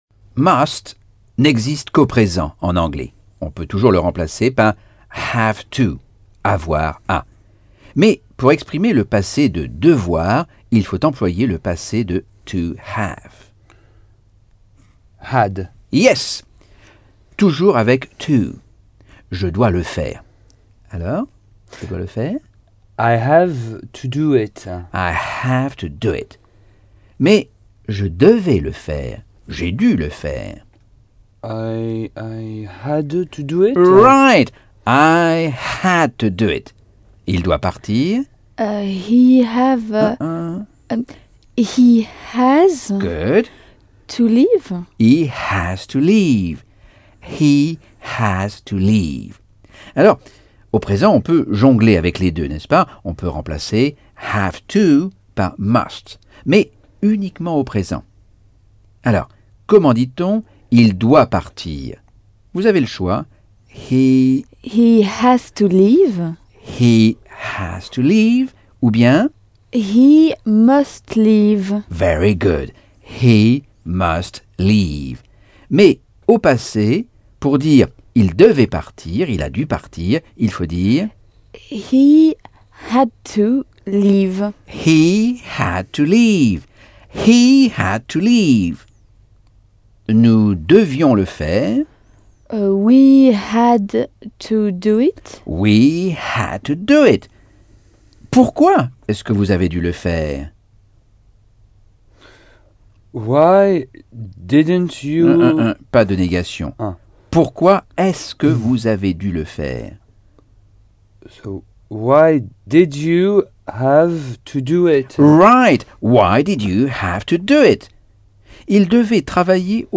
Leçon 6 - Cours audio Anglais par Michel Thomas - Chapitre 6